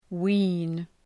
Shkrimi fonetik {wi:n}